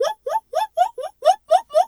Animal_Impersonations
zebra_whinny_02.wav